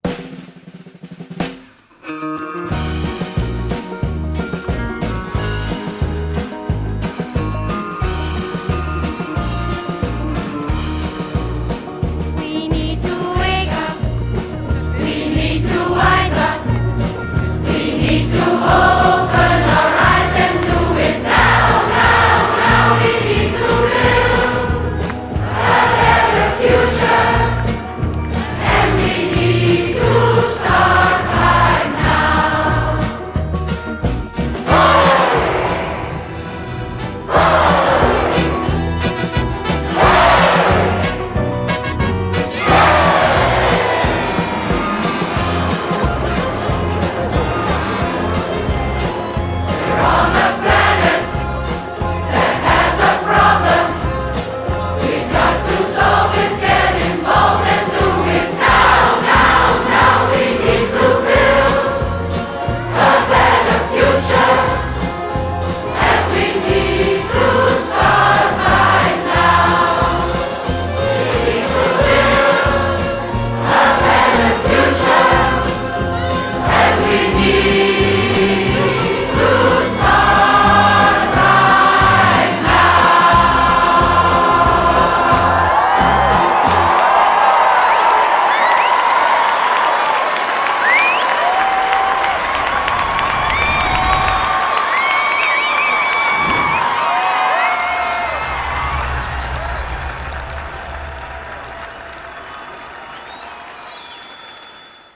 It all starter when the voice of 800000 people from 180 cities in Belgium joined on September 22nd and 23rd 2012, to manifest themselves on the planet.